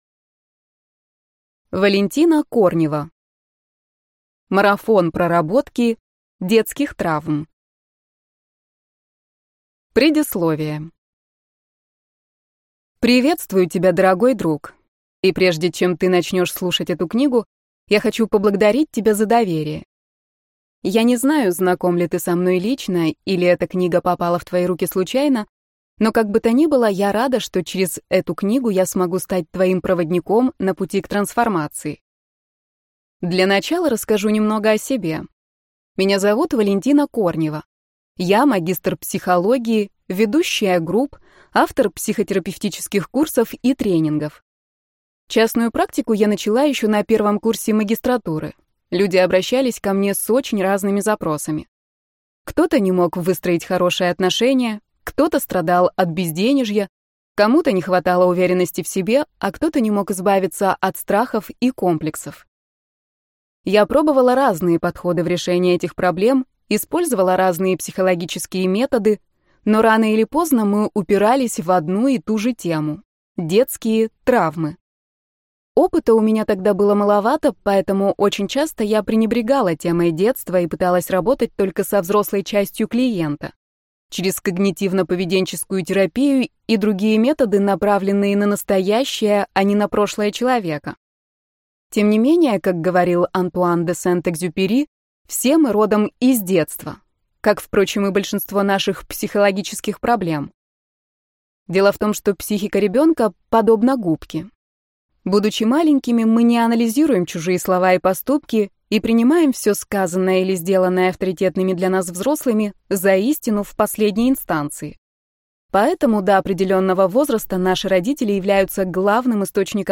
Аудиокнига Марафон проработки детских травм. Создаем внутреннюю опору и исцеляем себя | Библиотека аудиокниг